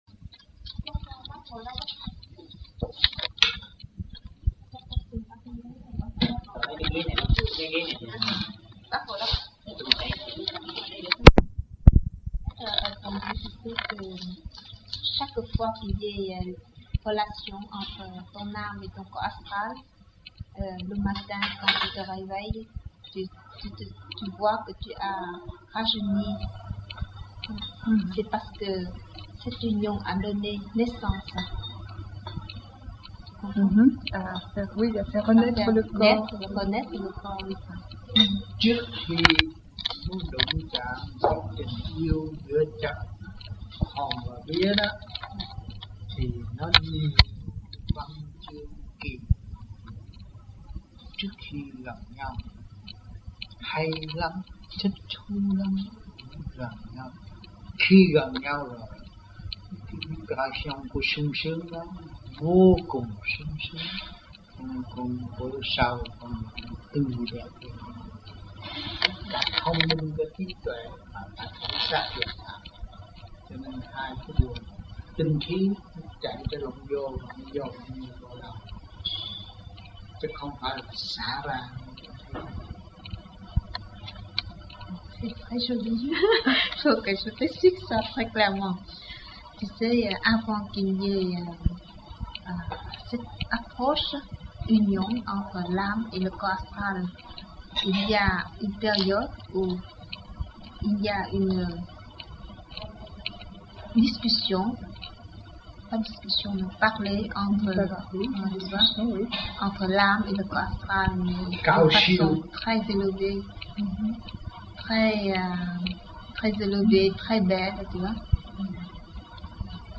1980-12-07 - GIEN - THẦY ĐÀM ĐẠO TẠI NHÀ THƯƠNG GIEN